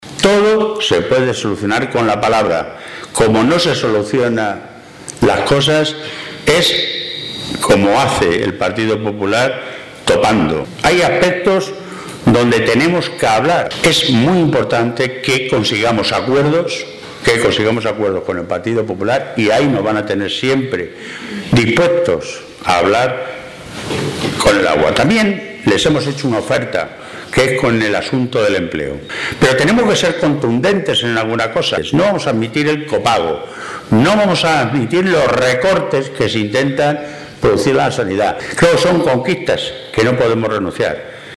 El secretario de Organización del PSOE de Castilla-La Mancha, Jesús Fernández Vaquero, ha abierto el XIII Congreso Provincial del partido en Guadalajara con un ofrecimiento de diálogo al PP para resolver cuestiones fundamentales para nuestra región, pero subrayando que “los socialistas seremos contundentes en la defensa de las conquistas sociales a las que no podemos renunciar: no admitimos el copago, ni los recortes, ni la privatización de la sanidad”.